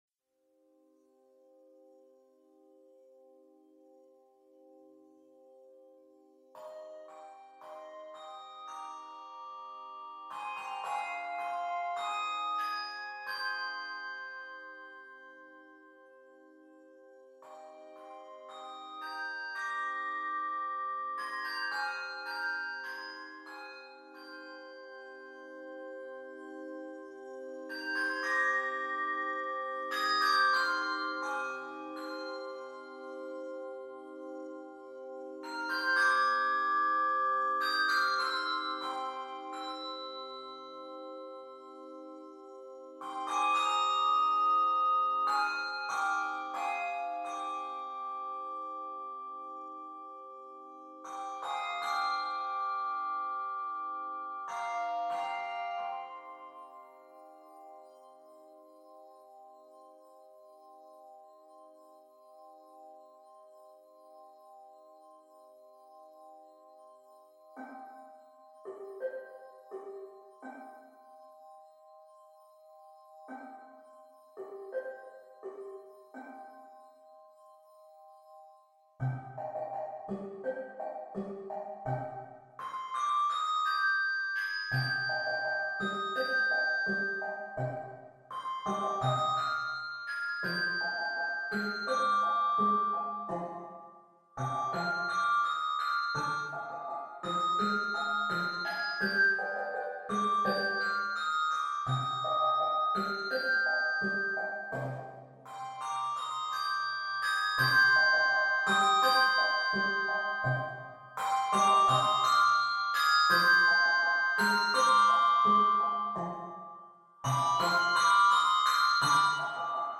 rhythmic, original Latin work